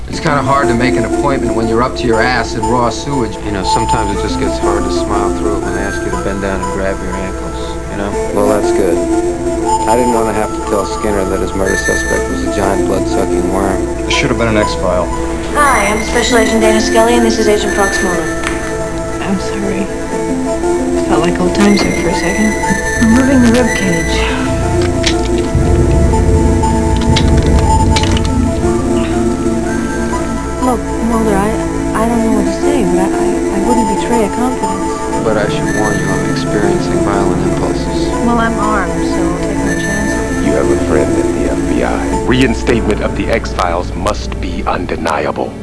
This sound sample or audio image comprises 45.62 secs of digitised speech, spoken by humans.